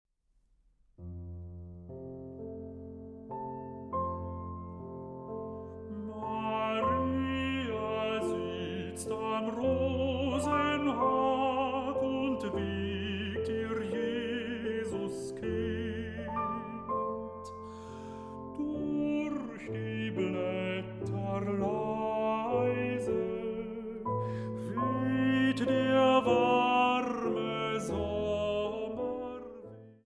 Bariton
Klavier